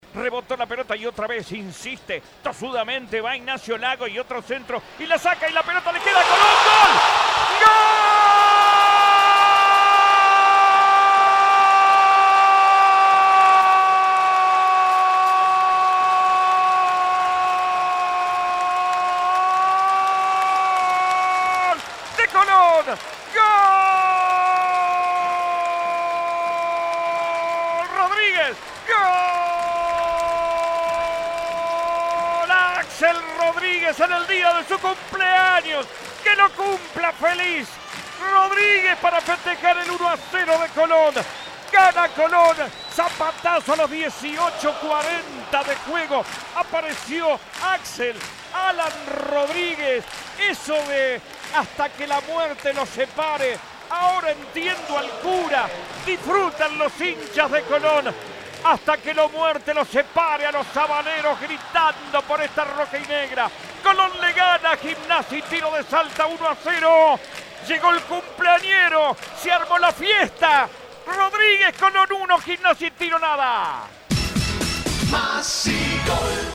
relato
GOL.mp3